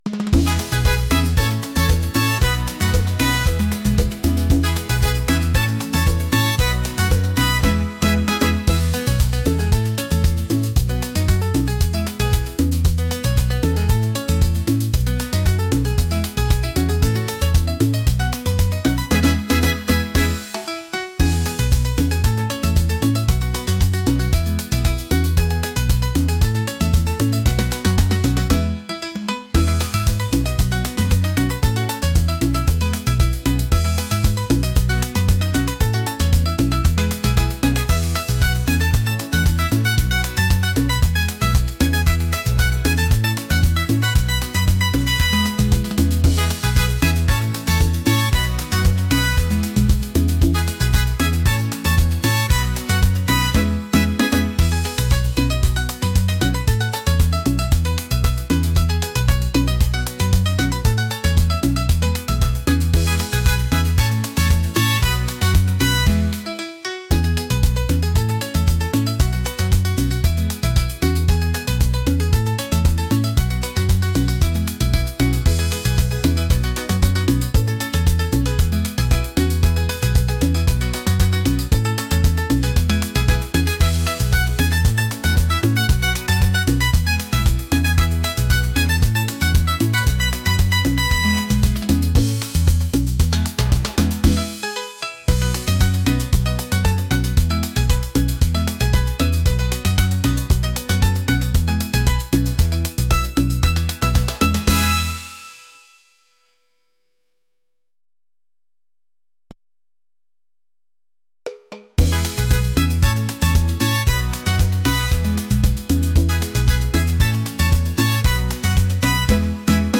upbeat | latin